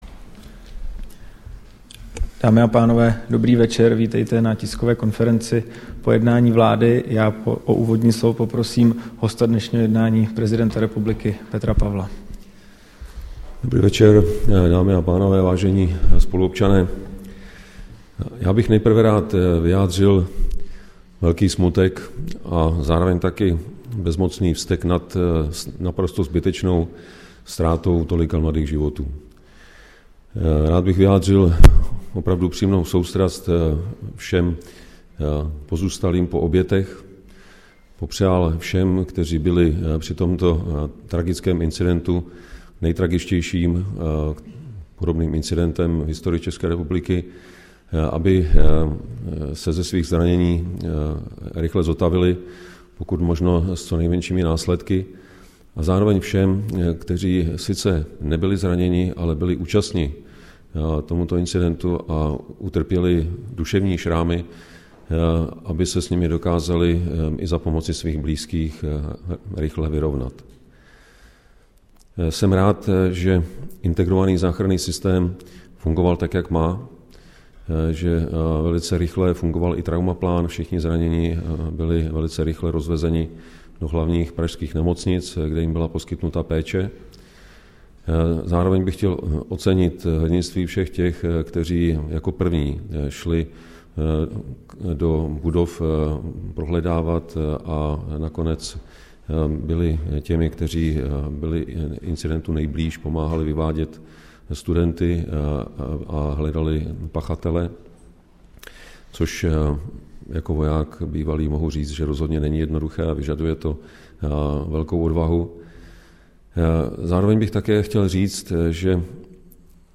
Tisková konference po mimořádném jednání vlády, 21. prosince 2023